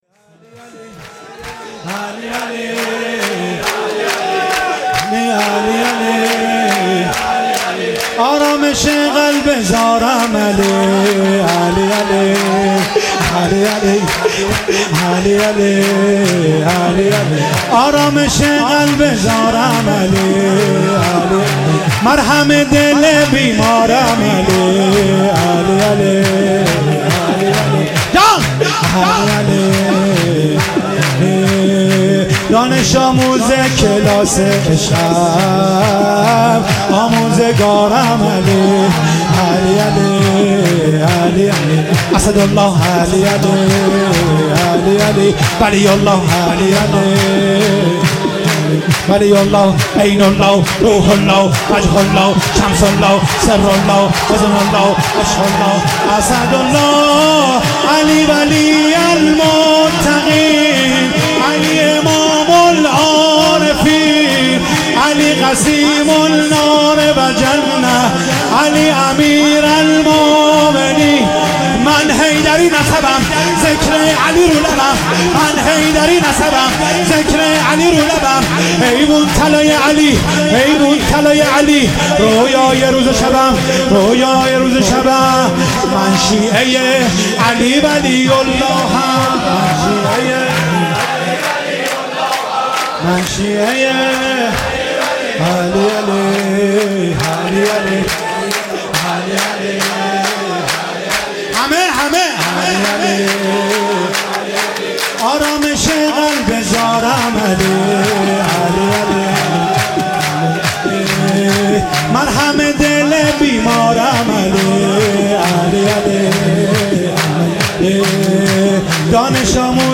ولادت امام حسن (ع)
شور